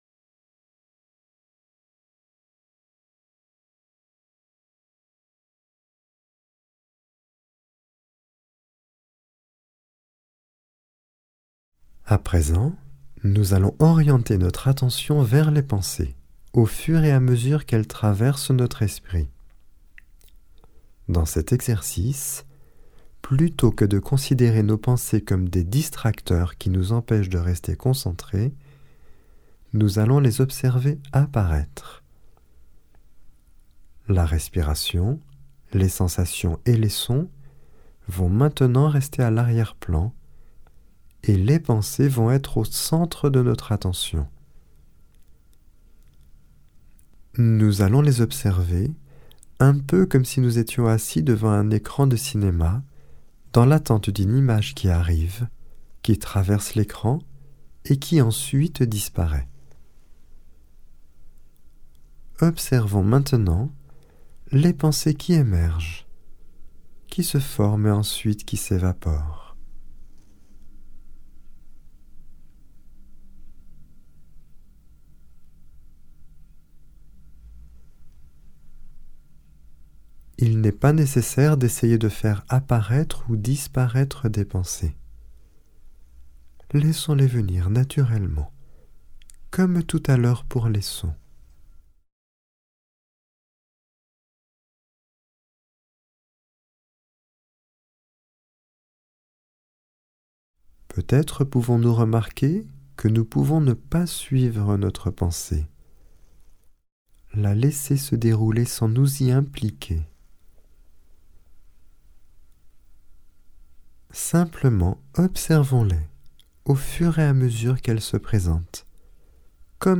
Genre : Meditative.
Etape 6 – Méditation assise complète 4